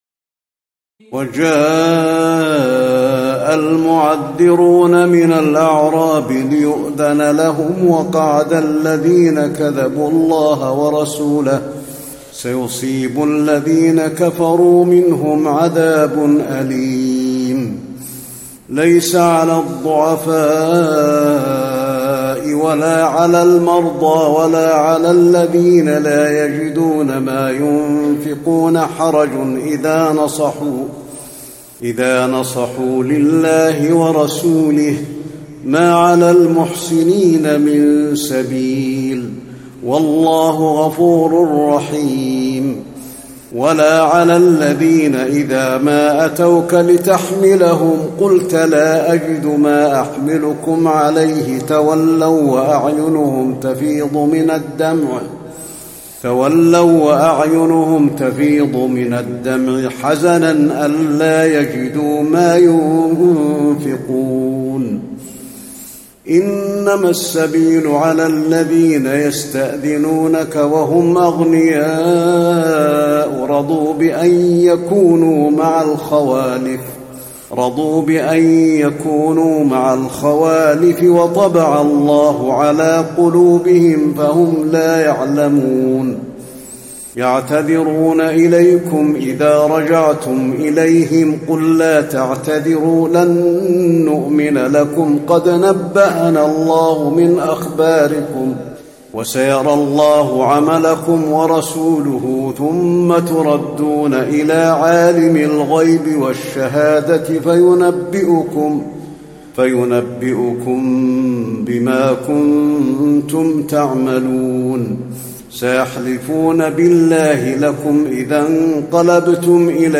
تراويح الليلة العاشرة رمضان 1435هـ من سورتي التوبة (90-129) و يونس (1-25) Taraweeh 10 st night Ramadan 1435H from Surah At-Tawba and Yunus > تراويح الحرم النبوي عام 1435 🕌 > التراويح - تلاوات الحرمين